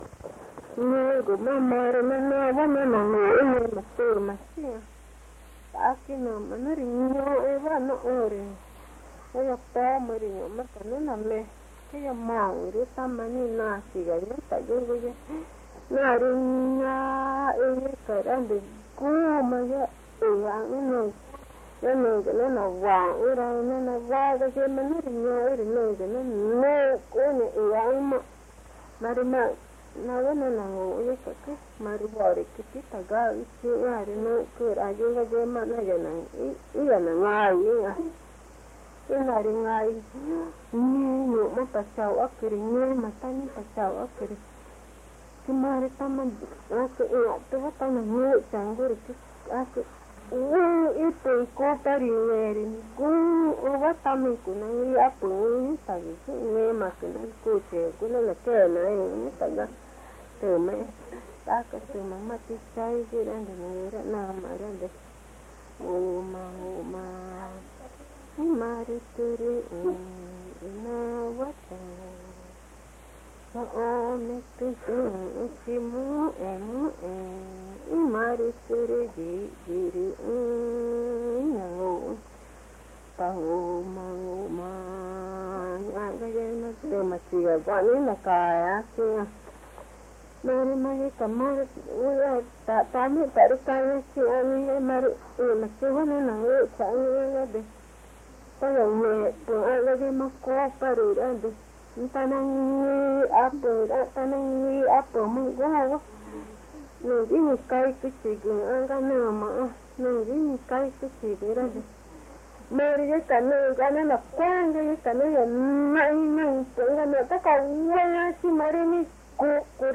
Puerto Nariño, Amazonas (Colombia)
Una abuela (anónima) cuenta en Magütá la historia del enmascarado (toü nguma).
A grandmother (anonymous) tells in Magütá the story of the masked character (toü nguma). This character is invisible, prepares for the Pelazón festival, and for the encounter with the (worekü) ‘pubescent girl’.